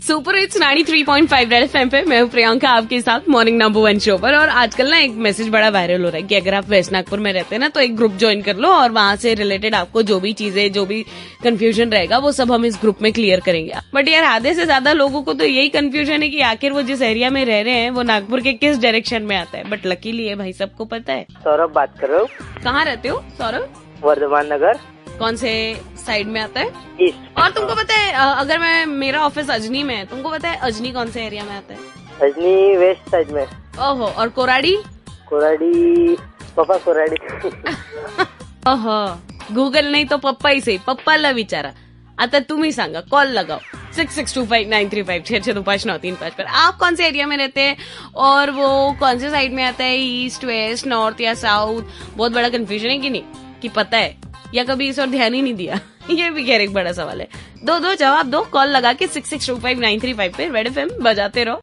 27JUL_L7_CALLER INTERACTION ON NAGPURI CONFUSION_